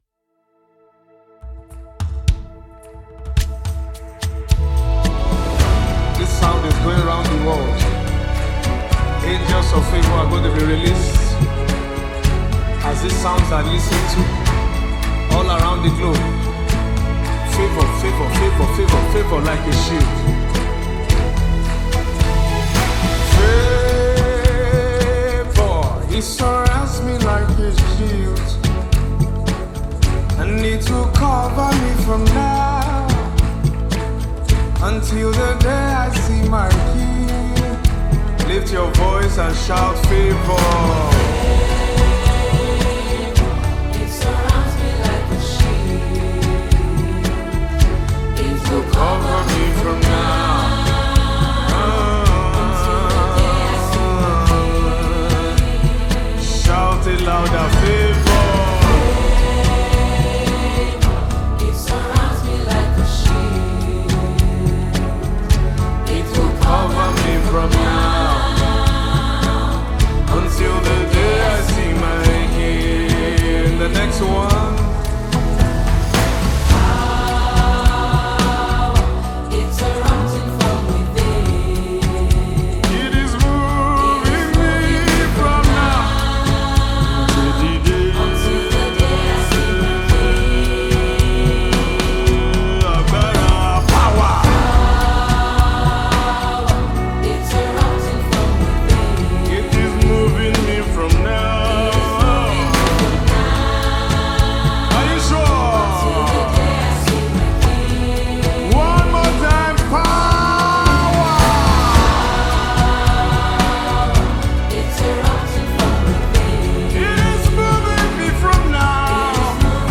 gospel
deep, spiritually charged music